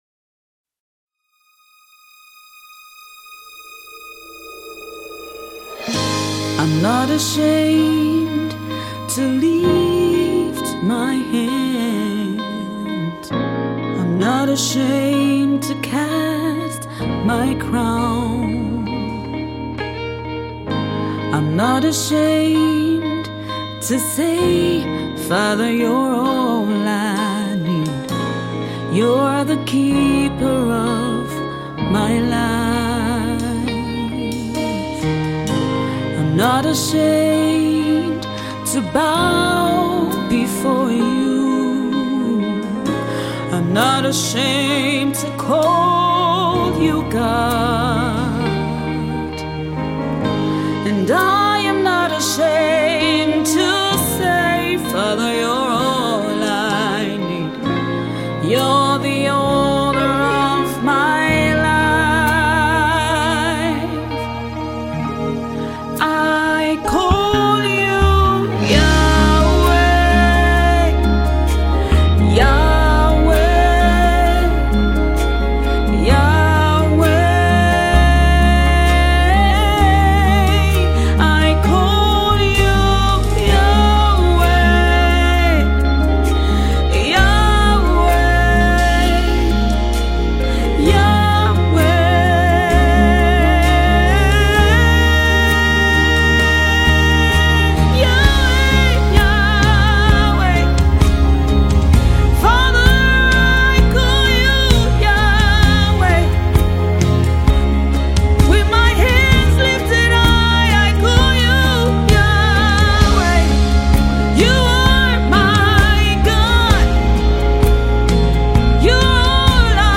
Gospel recording artiste
soothing melodic voice
gospel